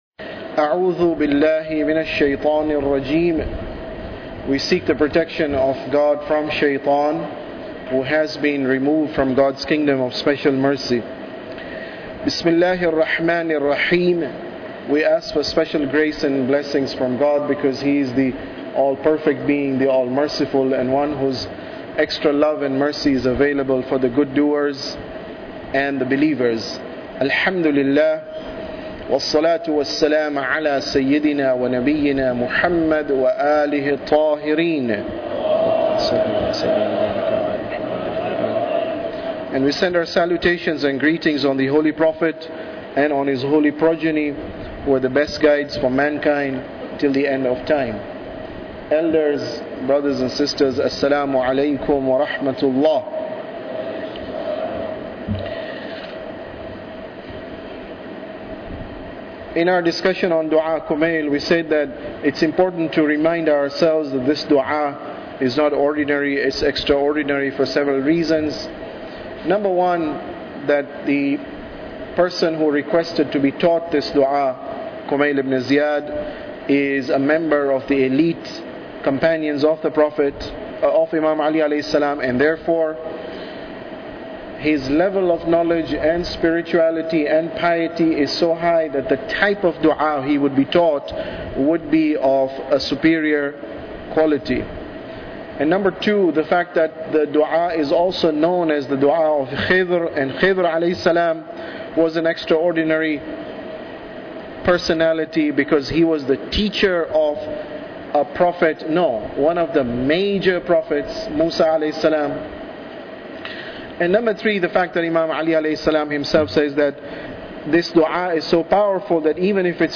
Tafsir Dua Kumail Lecture 22